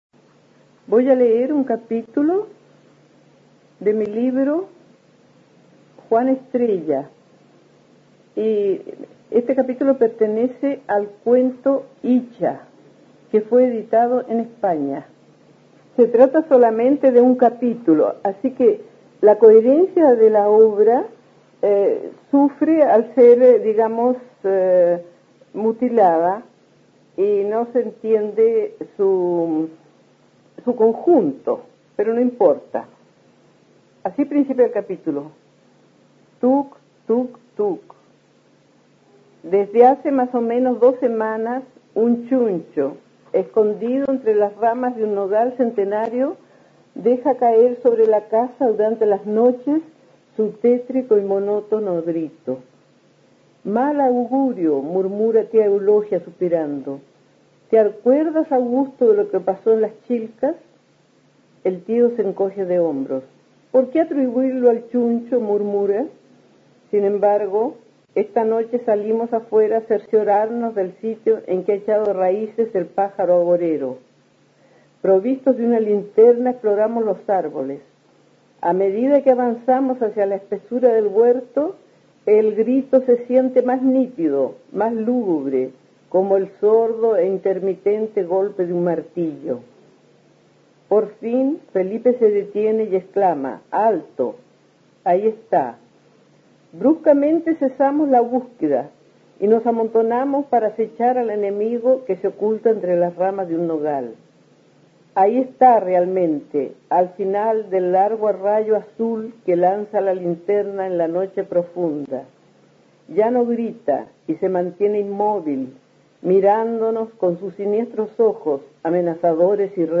Aquí podrás escuchar a la escritora chilena María Yánez (1898-1982) leyendo un capítulo de su libro "Juan Estrella".